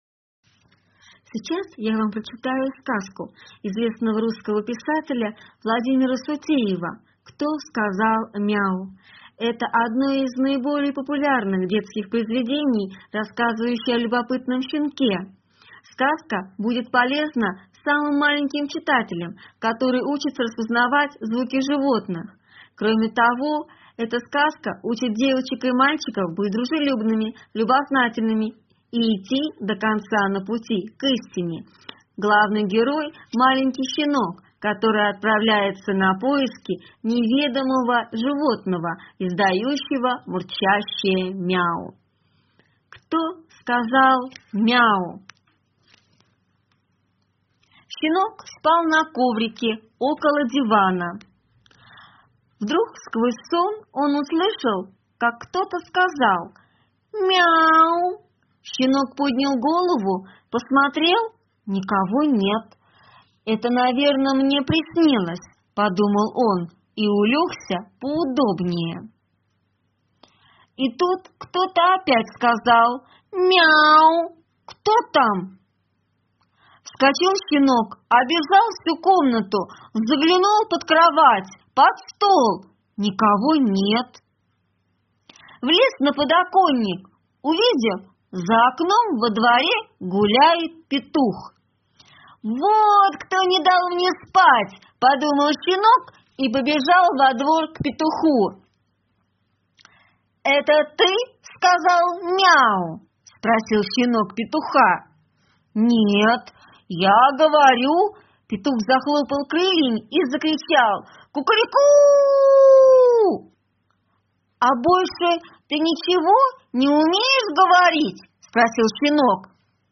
приготовилась прочитать для юных слушателей сказку В.Г. Сутеева про маленького щенка  «Кто сказал МЯ-У»?